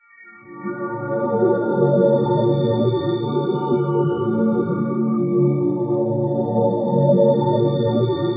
Index of /90_sSampleCDs/Spectrasonic Distorted Reality 2/Partition D/07 SCI-FI 1